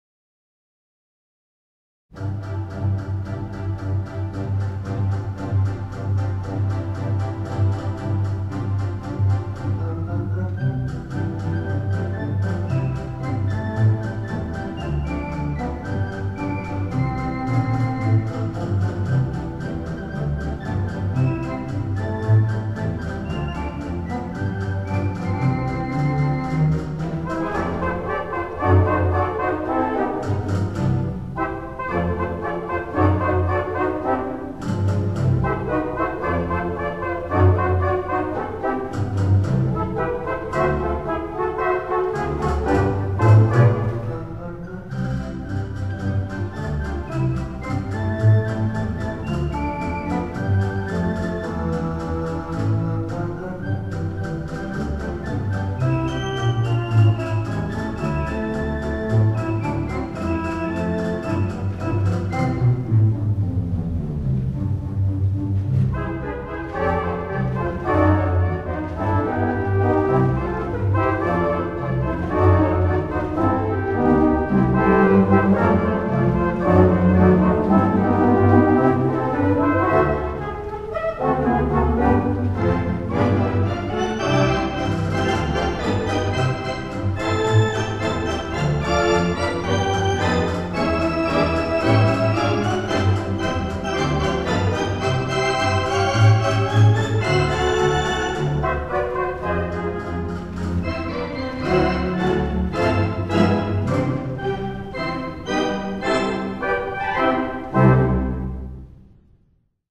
The Mighty WurliTzer on the stage
Big Band Sounds